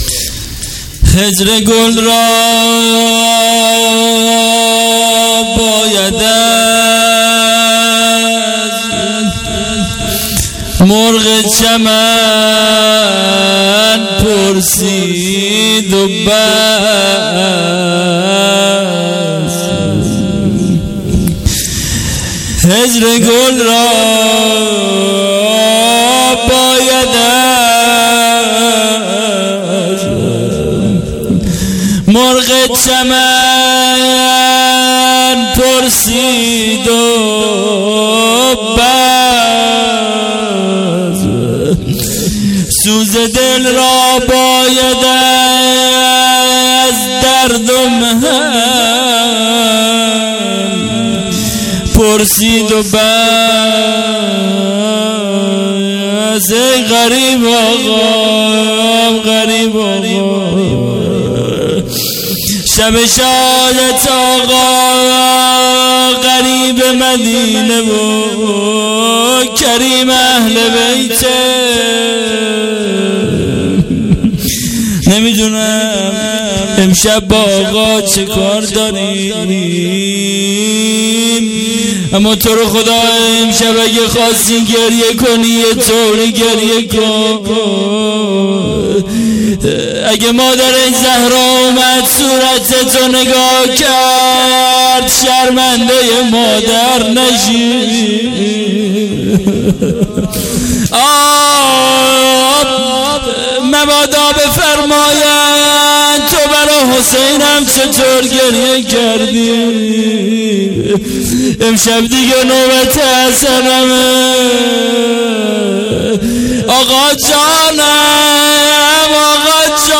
هیئت عاشورا-قم